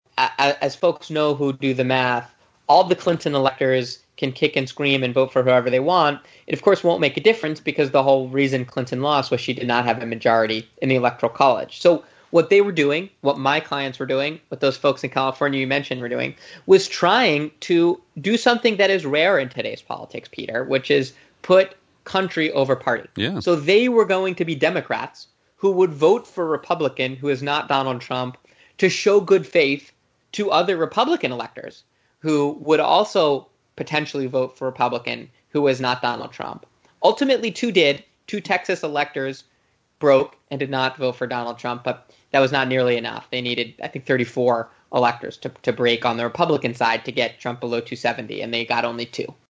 Attorney